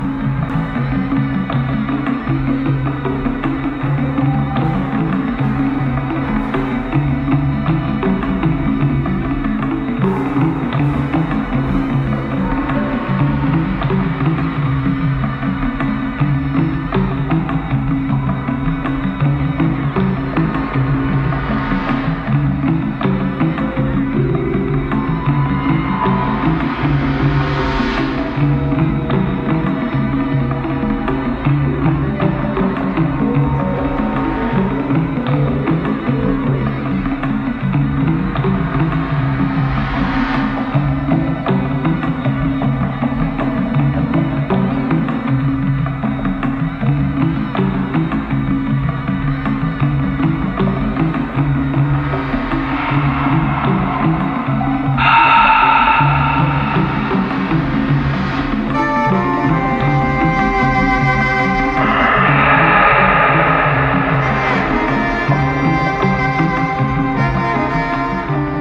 Electronix Wave Indie